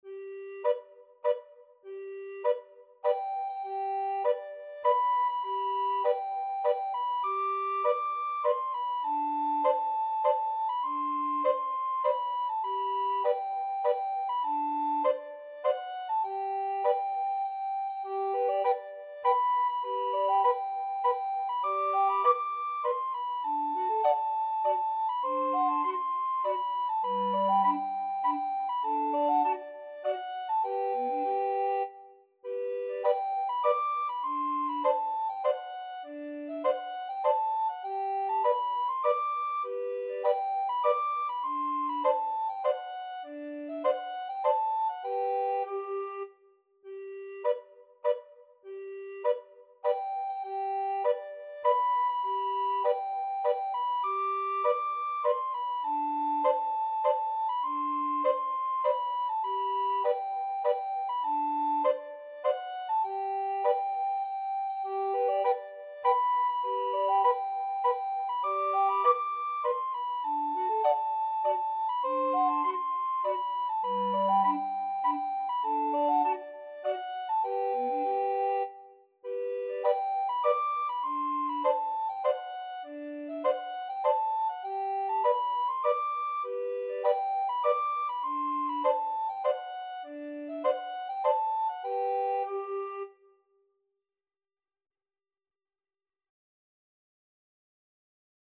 Free Sheet music for Recorder Trio
Soprano RecorderAlto RecorderBass Recorder
G major (Sounding Pitch) (View more G major Music for Recorder Trio )
Moderato
3/4 (View more 3/4 Music)
Traditional (View more Traditional Recorder Trio Music)